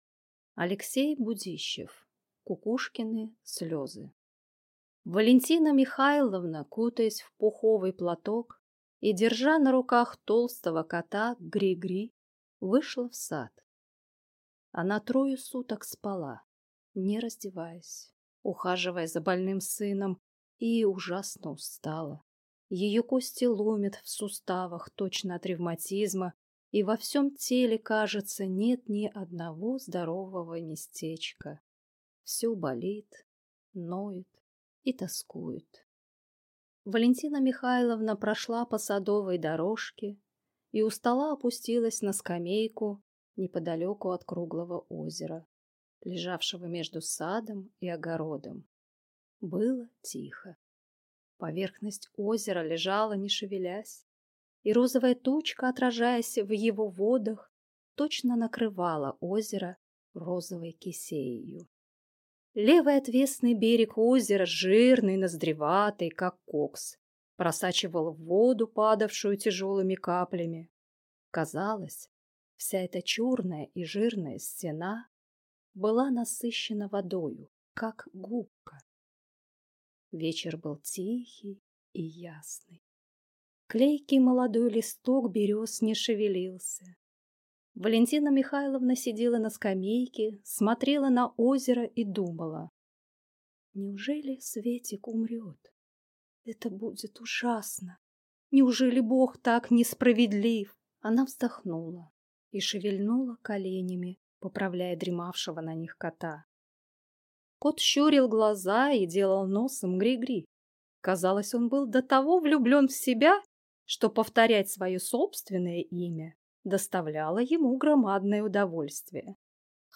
Aудиокнига Кукушкины слезы Автор Алексей Будищев